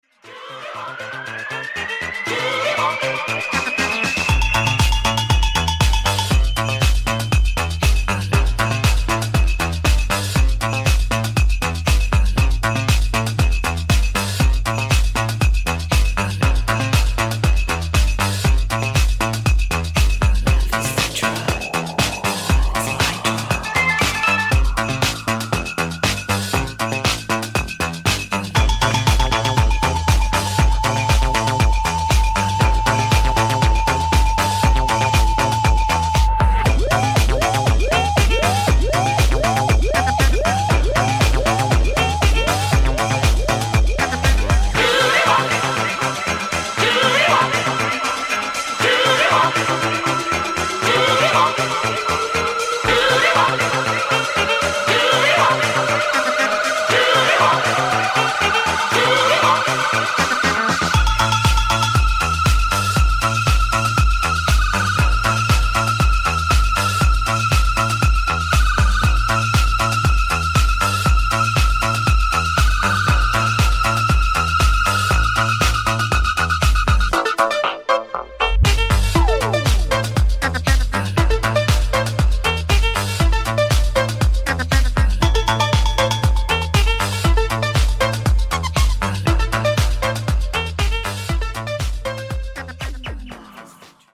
お勧めのディスコティックな一枚です！！
ジャンル(スタイル) DISCO HOUSE